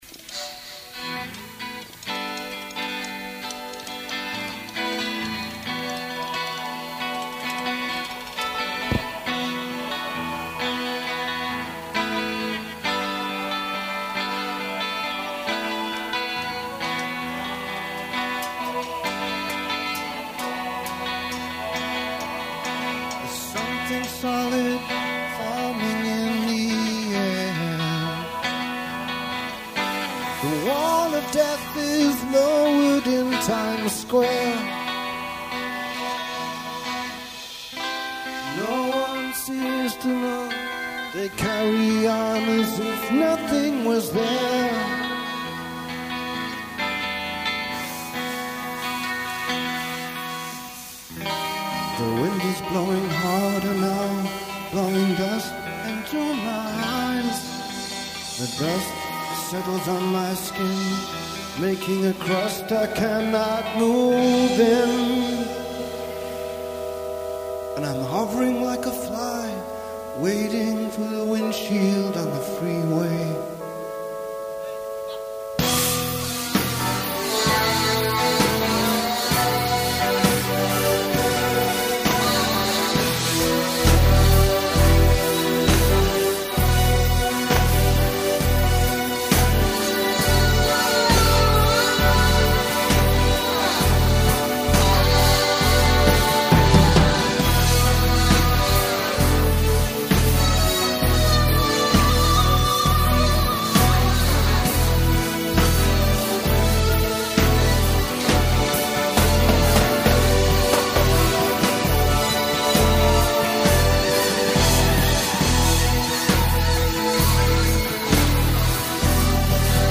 September 1982, Hammersmith Odeon, London.
Soundboard > Cassette